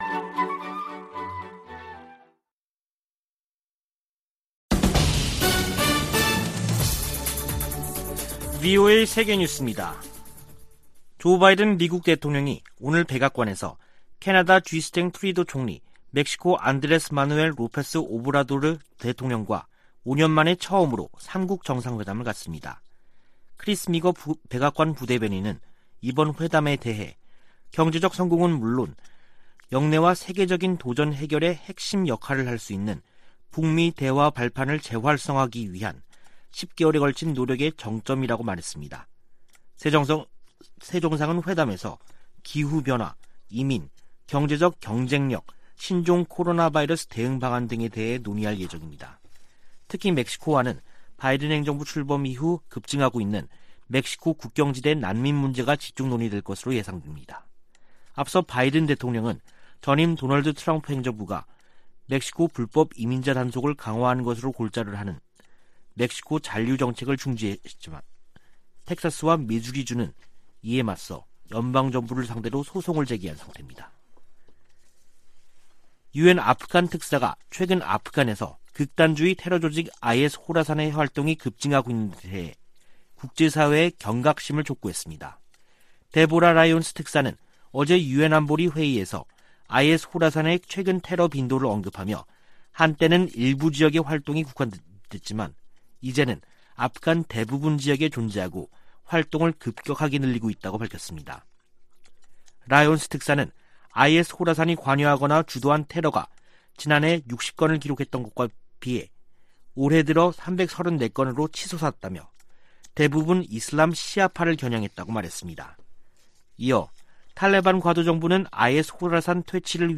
VOA 한국어 간판 뉴스 프로그램 '뉴스 투데이', 2021년 11월 18일 3부 방송입니다. 유엔총회 제3위원회가 북한 내 인권 침해에 대한 책임 추궁 등을 강조한 북한인권 결의안을 표결 없이 합의 채택했습니다. 미국 국무부가 북한을 종교자유 특별우려국으로 재지정했습니다. 웬디 셔먼 미 국무부 부장관은 종전선언을 비롯한 다양한 사안들을 협의했다며, 북한과 관련한 제재 이행과 함께 대화와 외교의 중요성을 강조했습니다.